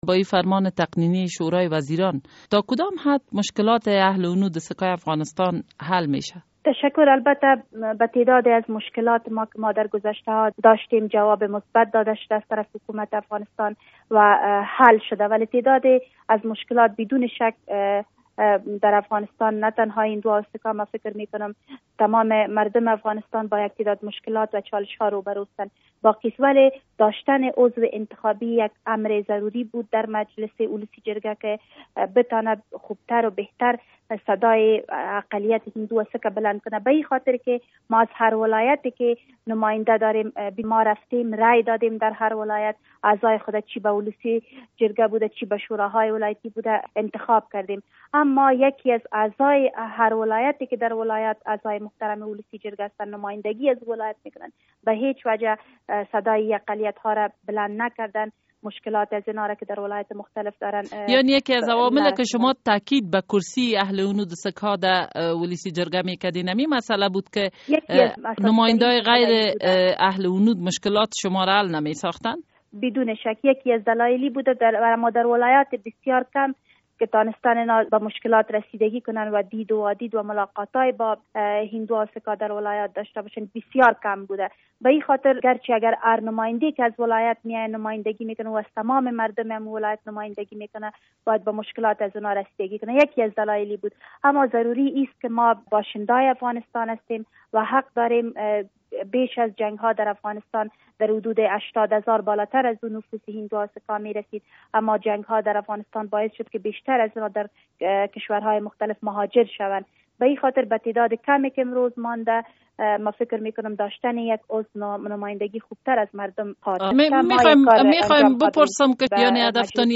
مصاحبه با انار کلی هنریار در مورد فیصله اخیر شورای وزیران